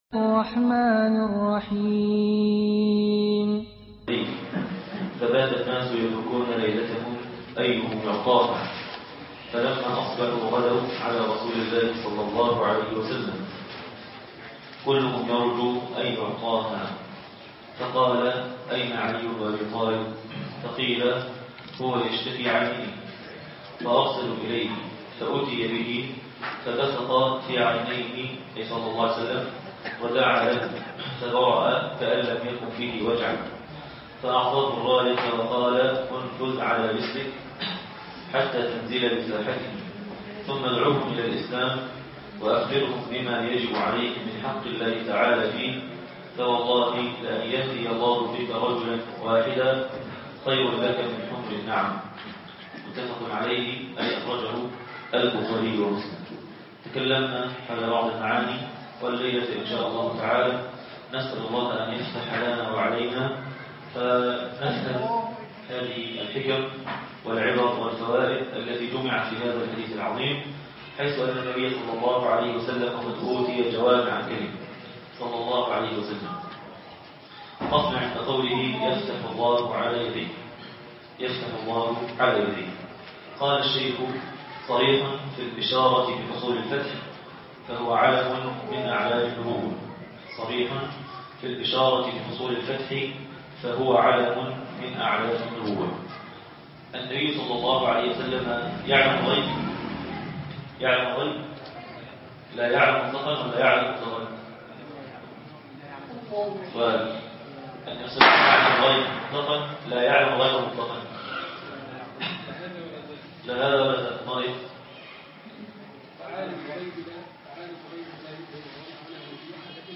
الدرس الثاني (فتح المجيد في شرح كتاب التوحيد